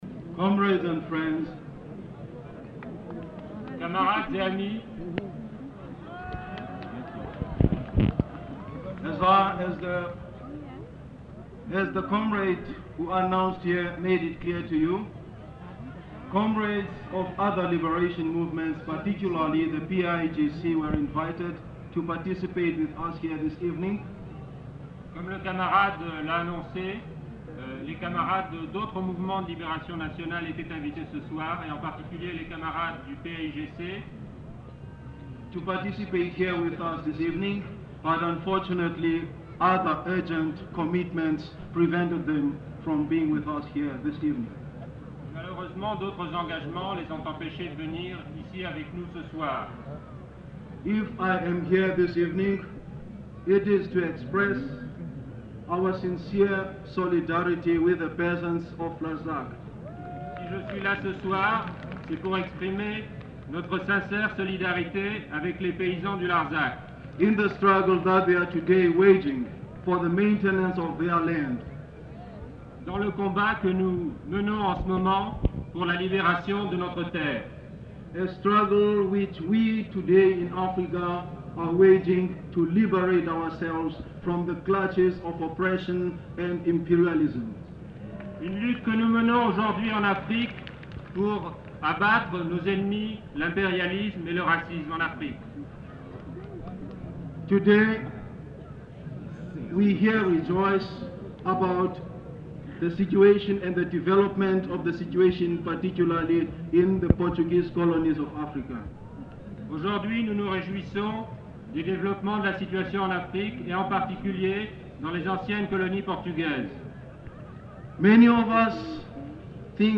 Numéro d'inventaire : 785-14 Plage CD/Page recueil : 5 Durée/Pagination : 14min 33s Dép : 12 Lieu : [sans lieu] ; Aveyron Date : 1974 Genre : parole Notes consultables : Les allocuteurs ne sont pas identifiés. Ecouter-voir : archives sonores en ligne Contenu dans [enquêtes sonores] Larzac 1974